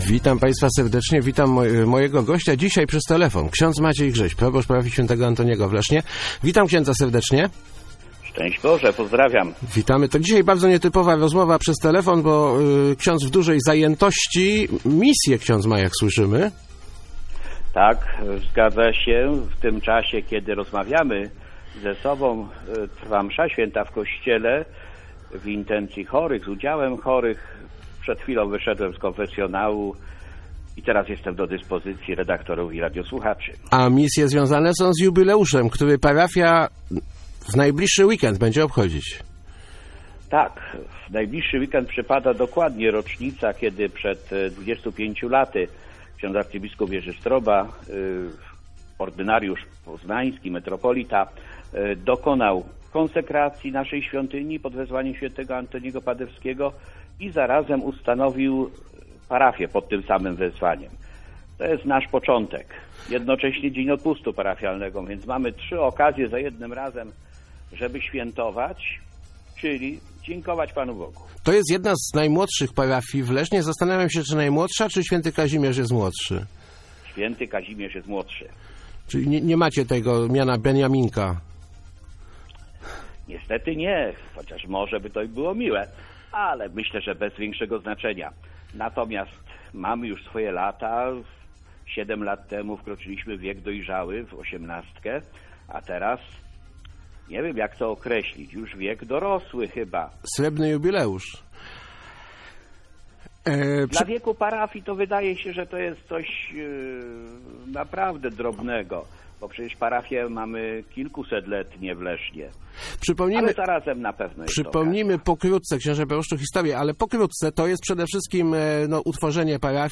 Start arrow Rozmowy Elki arrow 25 lat św.